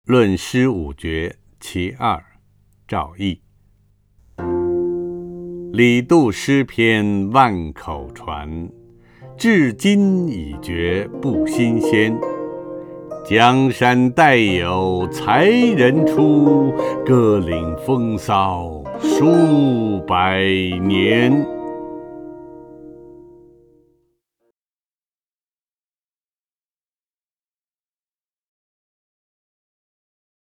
陈铎朗诵：《论诗五绝·其二》(（清）赵翼) （清）赵翼 名家朗诵欣赏陈铎 语文PLUS